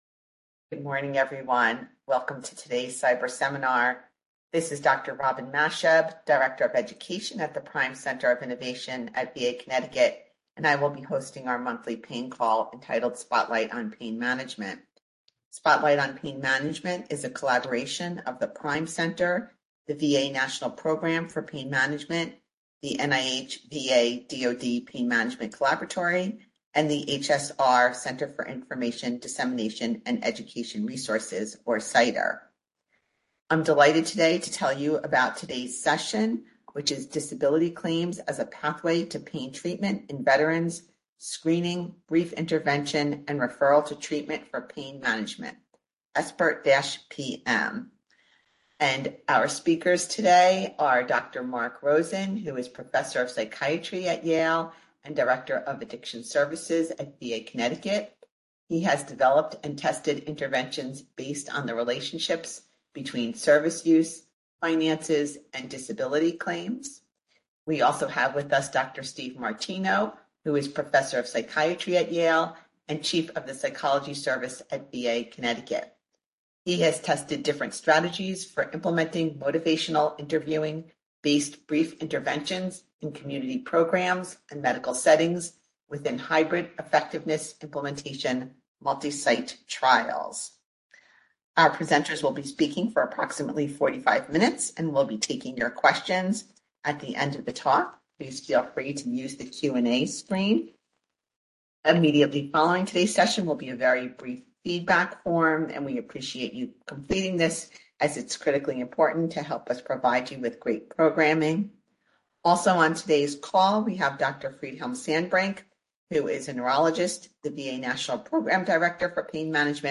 Description: The pain care pathway is difficult for some Veterans to navigate without assistance and motivational enhancement. SBIRT-PM, based on motivational interviewing, is like other treatment navigation approaches and has been associated with receipt of more outpatient treatment. The Presenters will describe use of pain care services among Veterans seeking service-connection for musculoskeletal disorders and an approach to implementing pain care navigation among case managers who work with Veterans recently separated from the military.